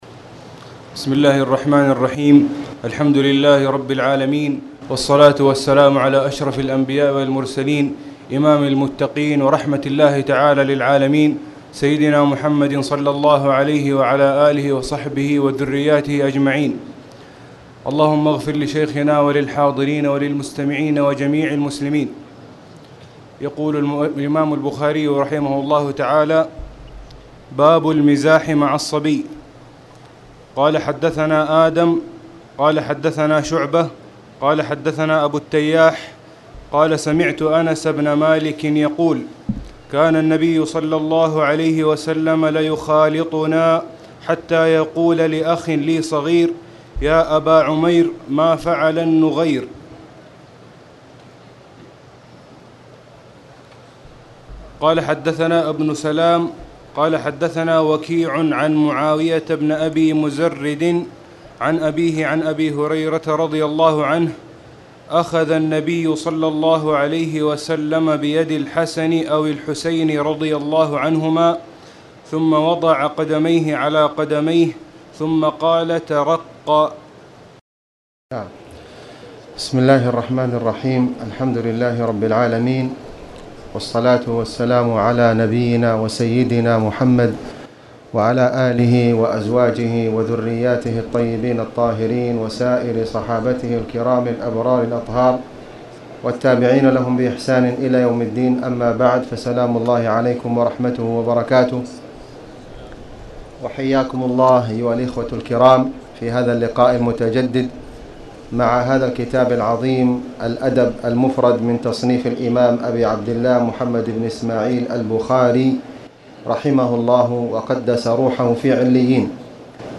تاريخ النشر ٧ جمادى الآخرة ١٤٣٨ هـ المكان: المسجد الحرام الشيخ: فضيلة الشيخ د. خالد بن علي الغامدي فضيلة الشيخ د. خالد بن علي الغامدي باب المزاح